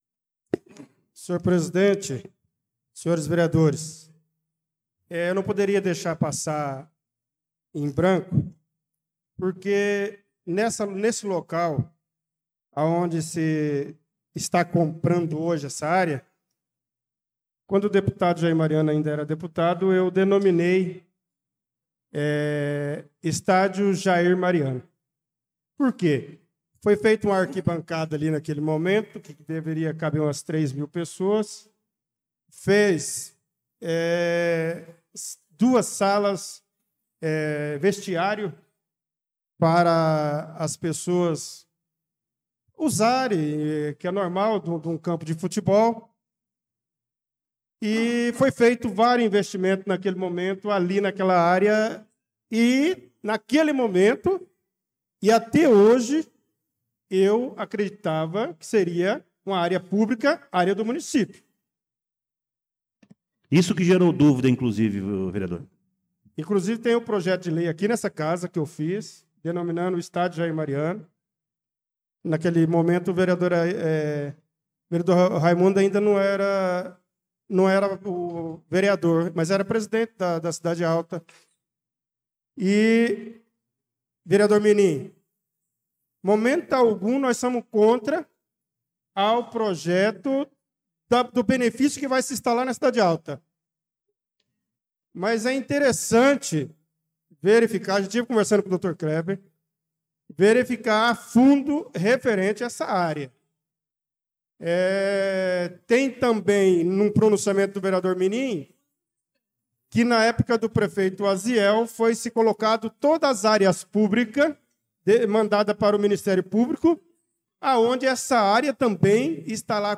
Pronunciamento do vereador Bernardo Patrício dos Santos na Sessão Ordinária do dia 11/02/2025